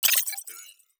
Robotic Game Notification 5.wav